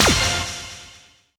VEC3 Percussion 020.wav